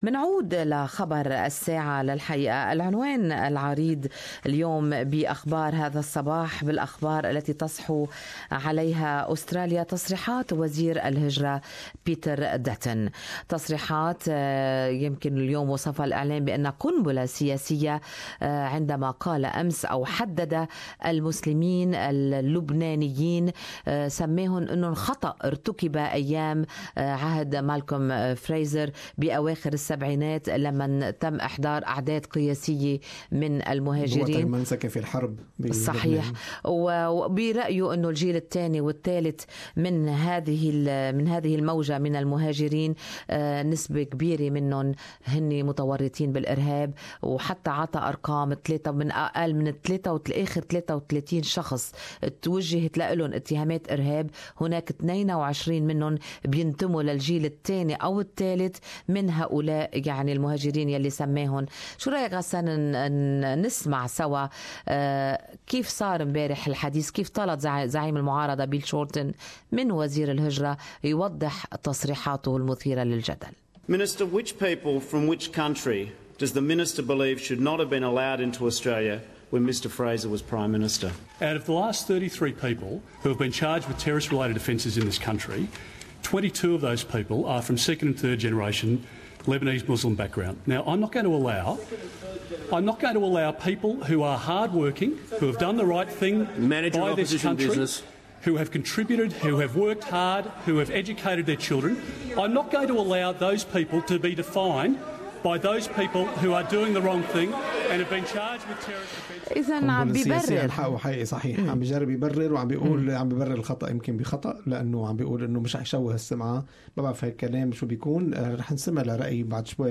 The reaction of the community in this talk back on Good Morning Australia.